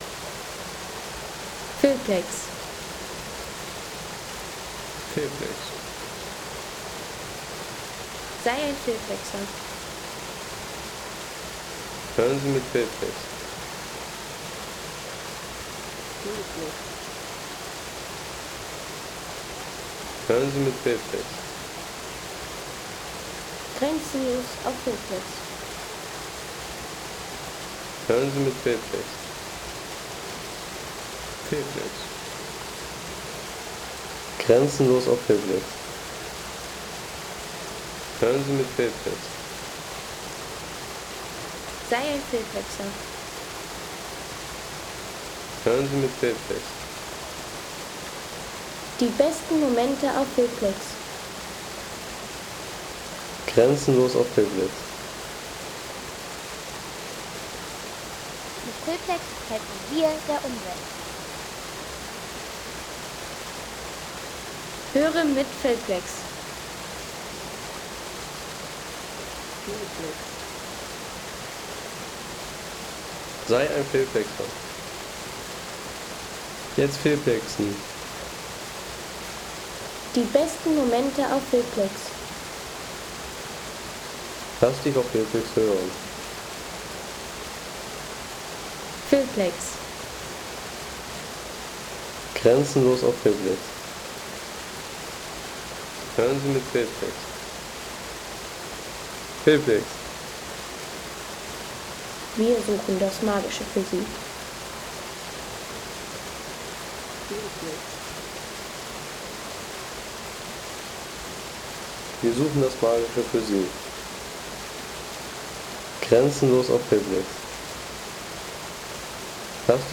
Gorge Echo – Nature Sounds from Lotenbachklamm, Black Forest
Peaceful waterfall and forest ambience from Lotenbachklamm – a cool, untouched soundscape from the Wutach Gorge in southern Germany.
A summer soundscape from the idyllic Lotenbachklamm – forest calm, gentle waterfall sounds, and natural coolness in the Wutach Gorge.